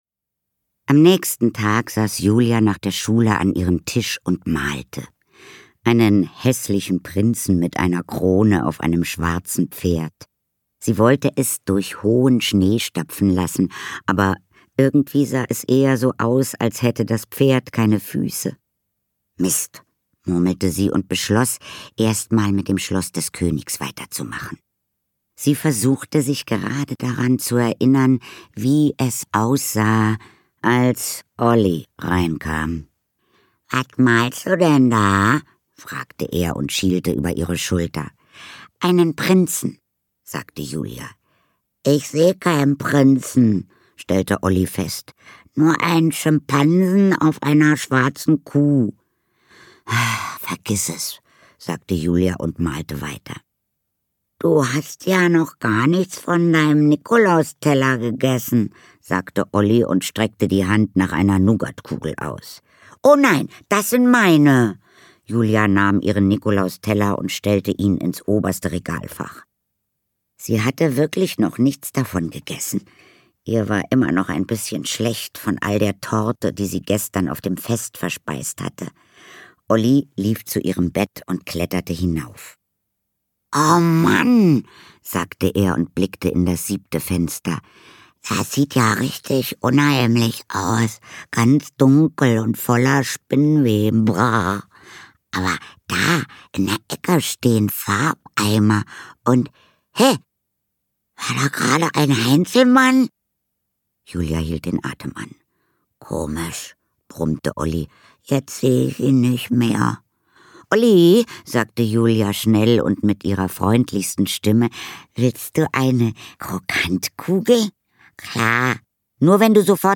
Katharina Thalbach (Sprecher)